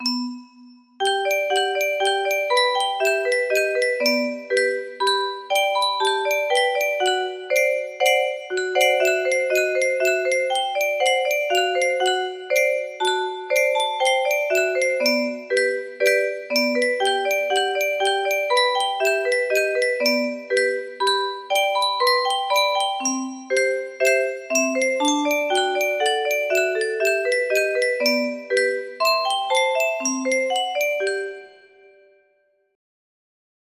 Clone of jetzt trink me noch a flaschel Wein music box melody